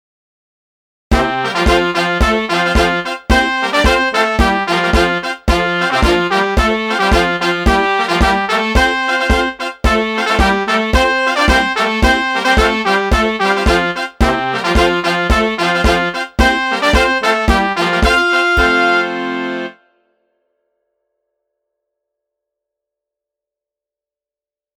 最初はゆっくりとしたノーマルものから、大部分の人ができるようになったときのレベルを上げてテンポを上げた音源も掲載。
110 Kintaro_temp110
Kintaro_temp110.mp3